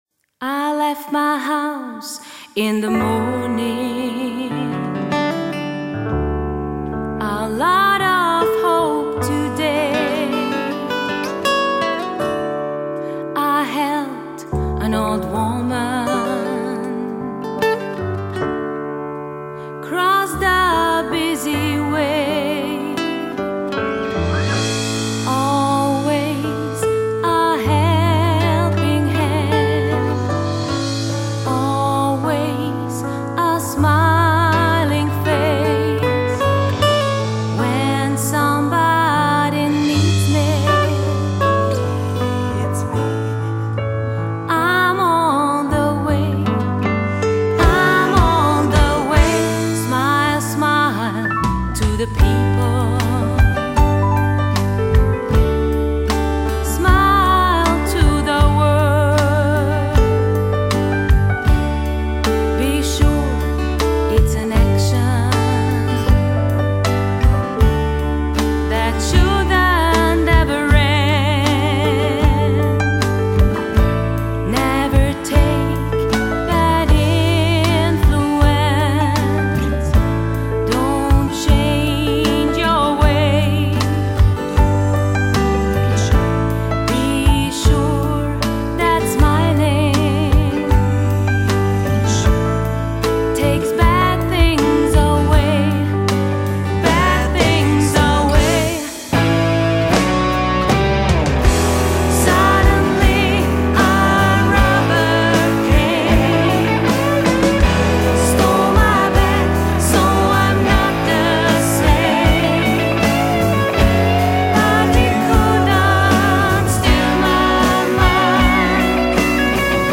Country-Band